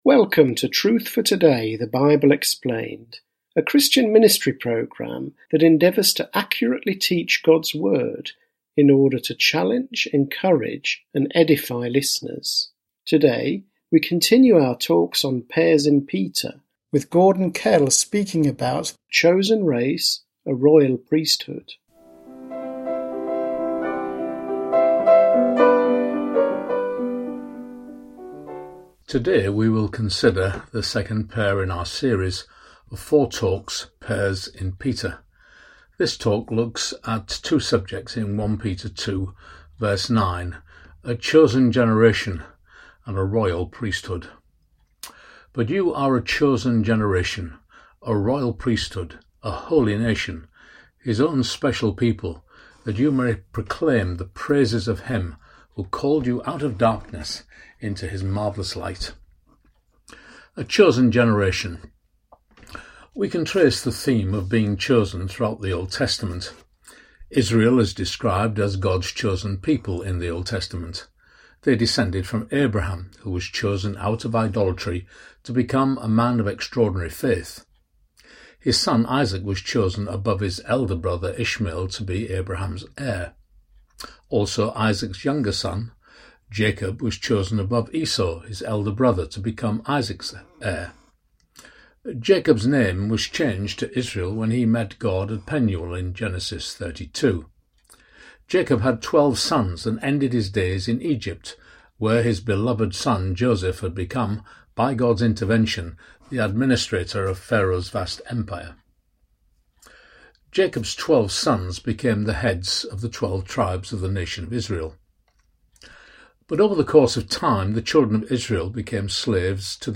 Truth for Today is a weekly Bible teaching radio programme.